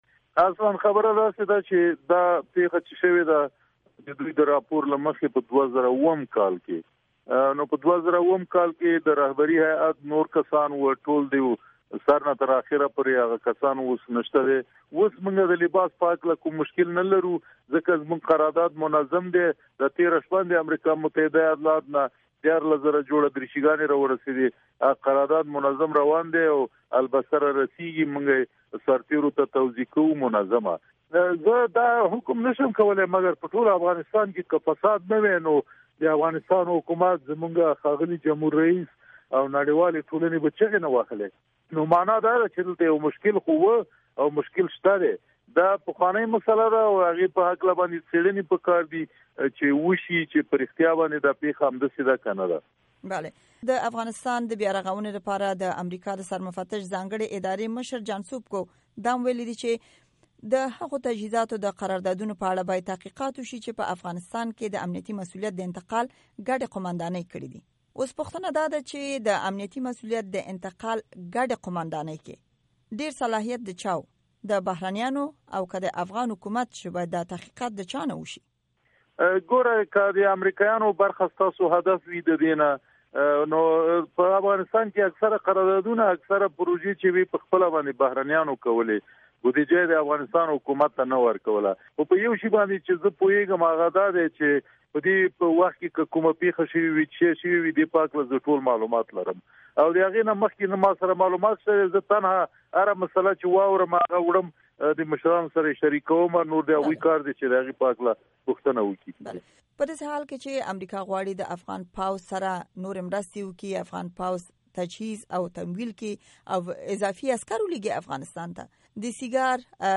د فاع وزارت وياند جنرال دولت وزيري سره مرکه دلته اوريدلائ شئ.
دفاع وزارت وياند سره د امريکا غږ مرکه